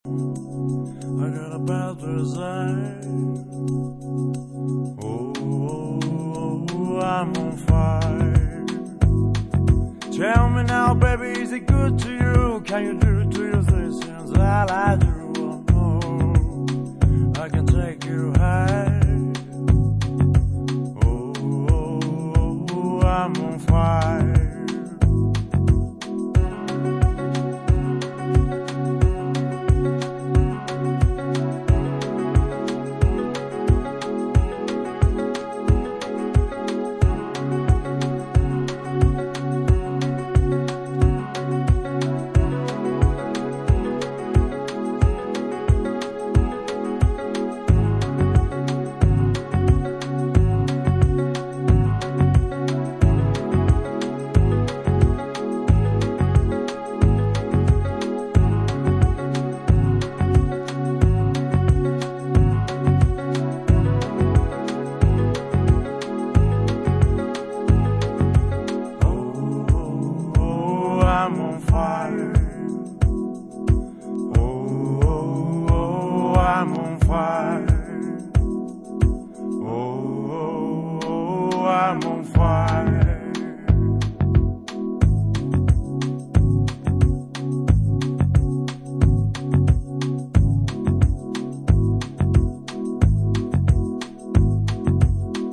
House Soul